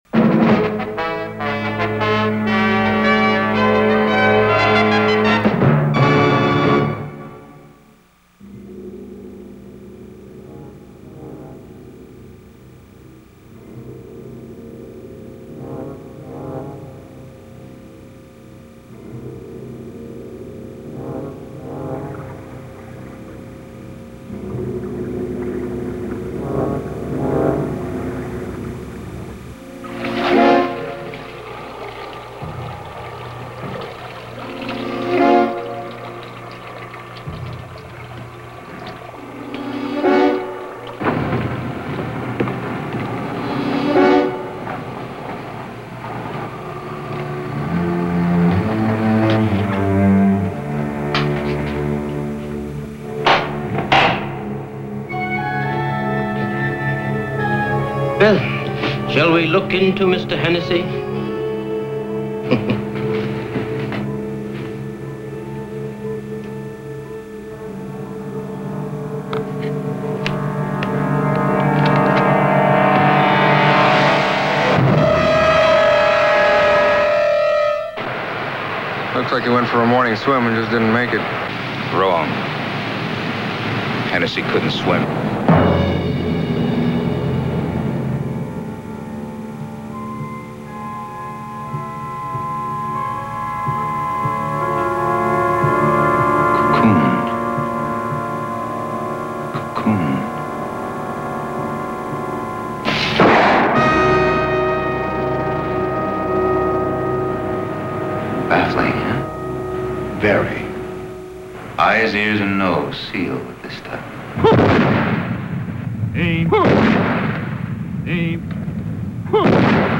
Teaser - "Previously On," almost 6 minutes in length (see below for more detail), there is a "Wave" at the beginning.
Some of the music in the "previously on" is what actually was heard in the show behind the included scenes, but the rest of it is dubbed in from other scenes.